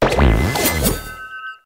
celuredge_ambient.ogg